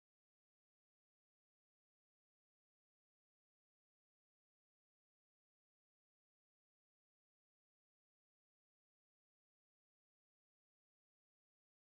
Kinderspiele: Verlorner Ring
Tonart: C-Dur
Taktart: 3/4
Tonumfang: kleine Septime
Besetzung: vokal